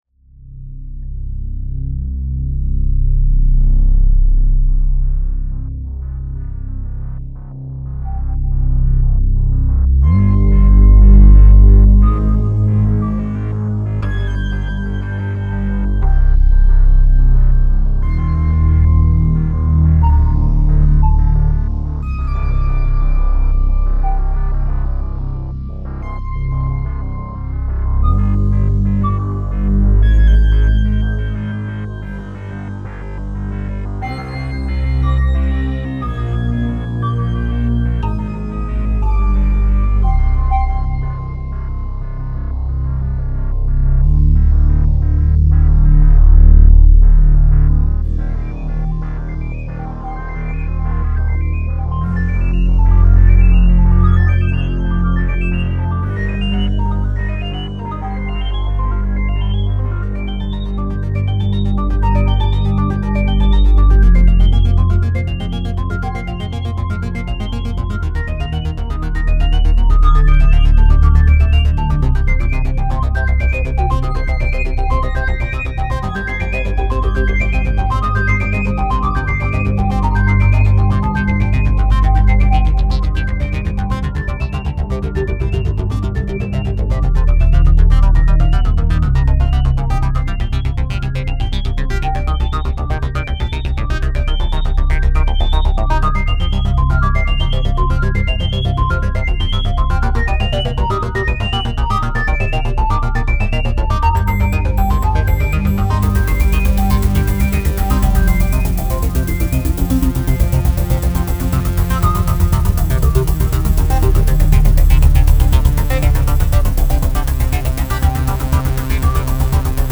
A trip through dark and light in three movements.